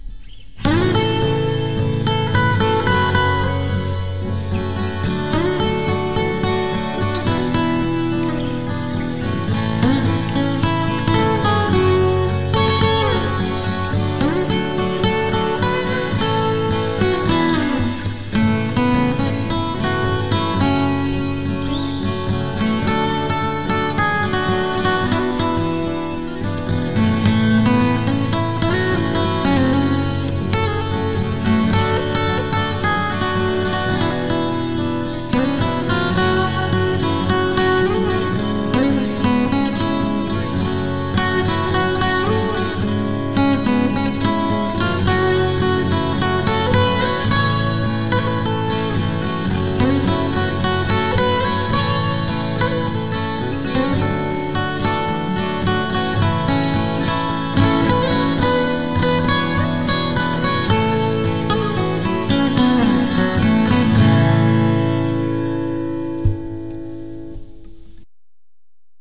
一般的な鉄弦のアコースティックギターに比べると、伸びのある甘いサウンドです。聞き慣れないサウンドともいえますが、ソロギターで使うには適したサウンドです。
Sound of Bristlecone & Martin D28(Backing)　Recorded by RODE NT1-A